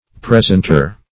Presenter \Pre*sent"er\, n.